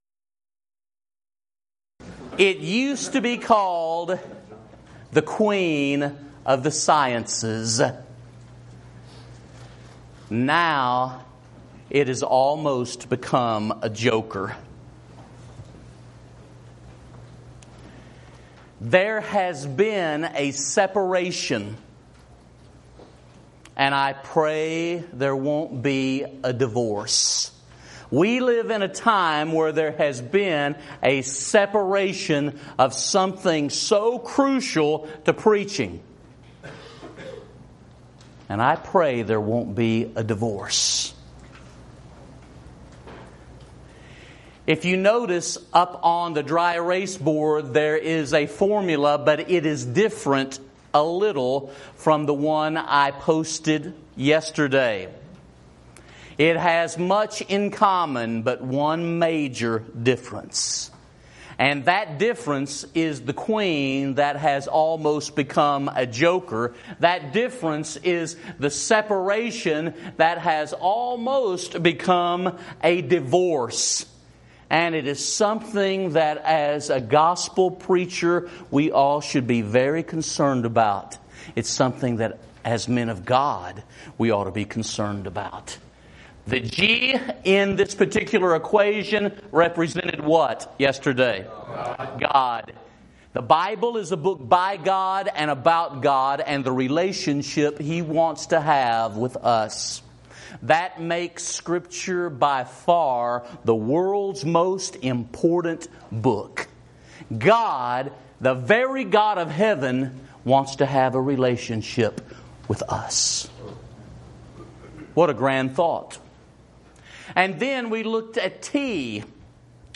Event: 2017 Focal Point
lecture